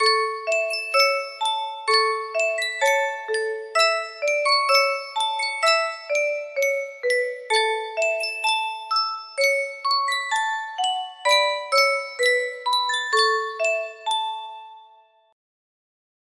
Sankyo Music Box - Antonin Dvorak Symphony No. 9 EJ music box melody
Full range 60